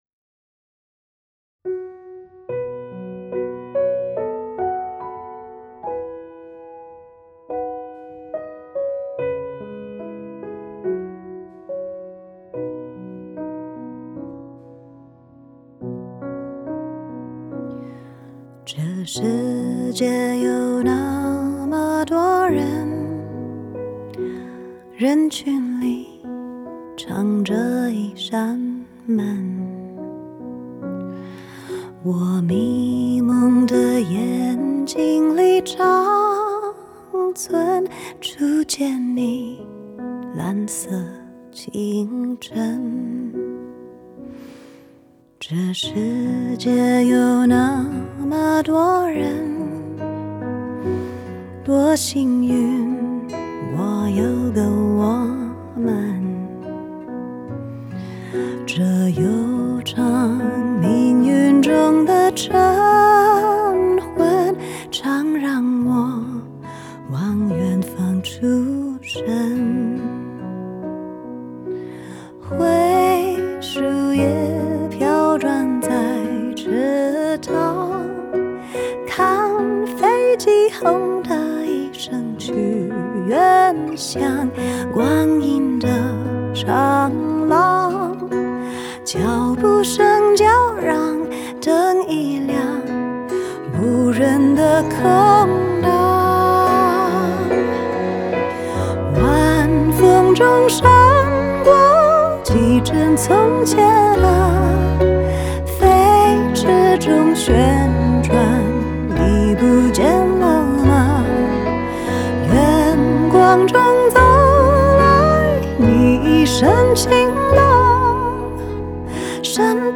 Ps：在线试听为压缩音质节选，体验无损音质请下载完整版
Strings所有弦乐器
Guitar吉他
Mandolin曼陀林
Drums鼓